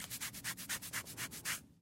Звуки чесания
Звук принятия человеком головы или волосами